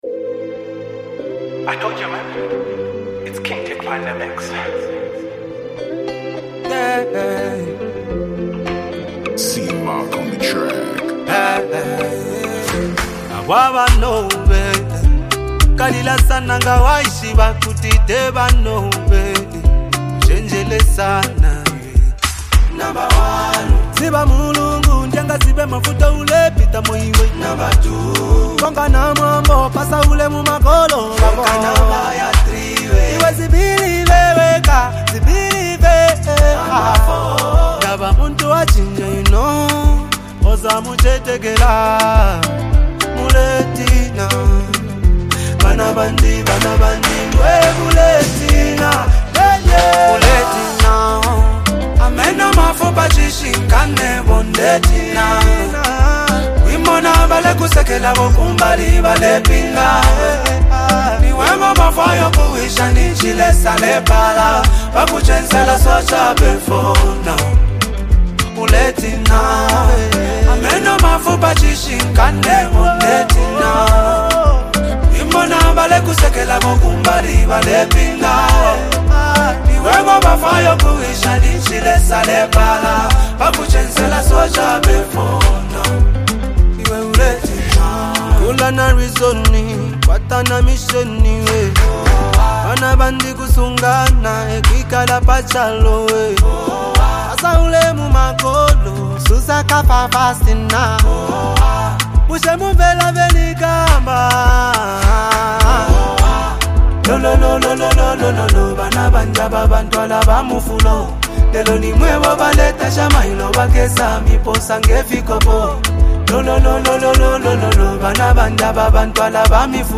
It blends Afrobeat, R&B, and local sounds